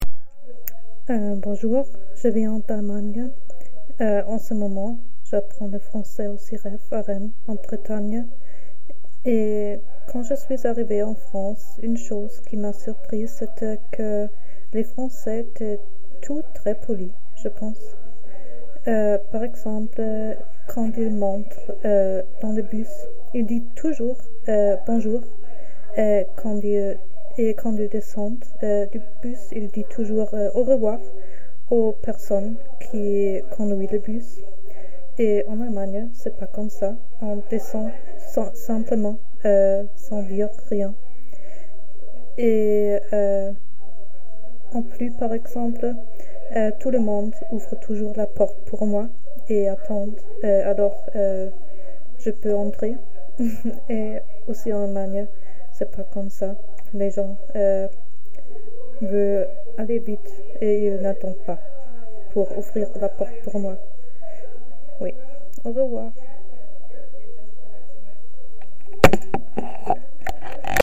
Cabine de témoignages
Témoignage du 24 novembre 2025 à 19h20